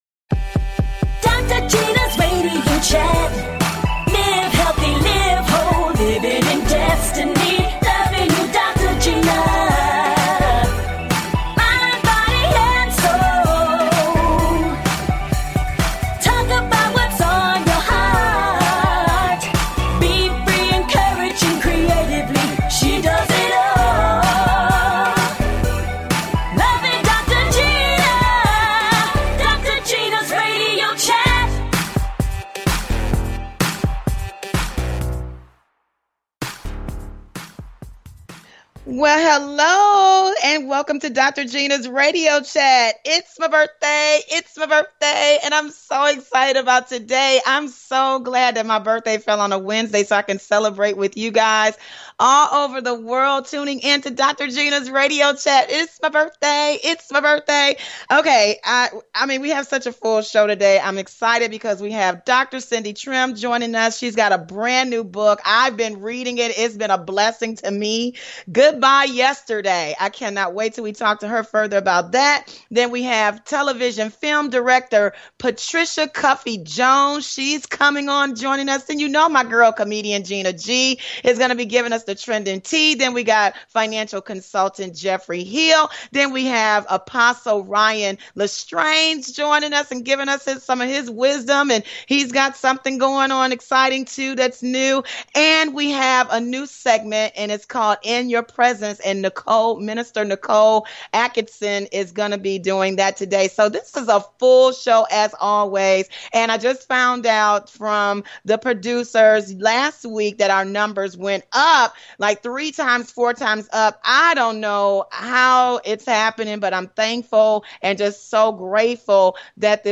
And full of laughter!
A talk show of encouragement.